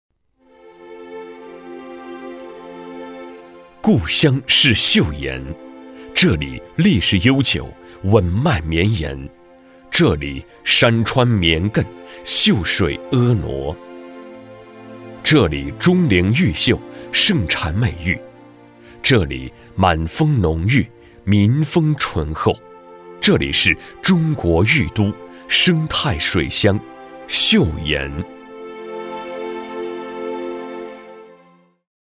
男25-城市乡村宣传片- 舒缓
男25-城市乡村宣传片- 舒缓.mp3